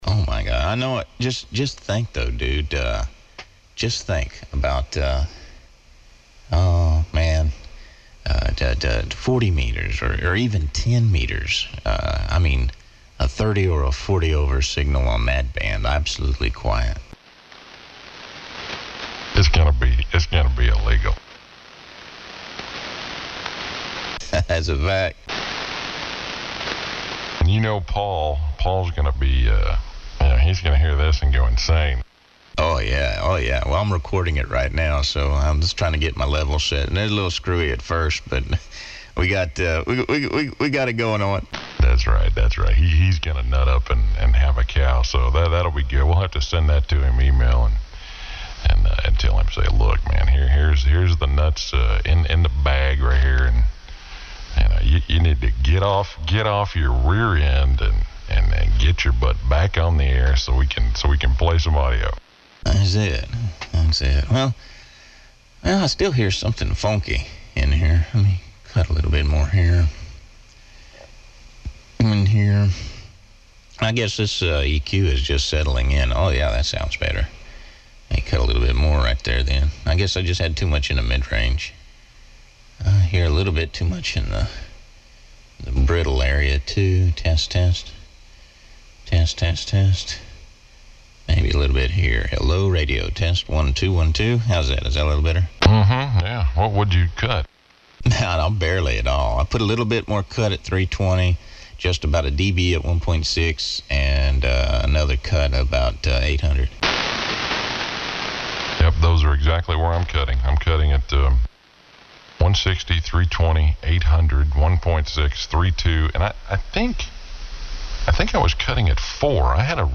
This is in SSB, and it is just as clean as the original bandwidth.
The Clarity and Openness is just plain Incredible.
We´ve also added a recording of some Beta Testing.
This is SSB!!! This Audio is from the Transceiver!!! This is UNBELIEVABLE!!